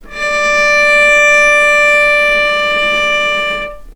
vc-D5-mf.AIF